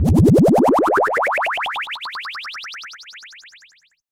fxpTTE06001sweep.wav